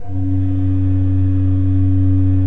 Index of /90_sSampleCDs/Propeller Island - Cathedral Organ/Partition H/KOPPELFLUT M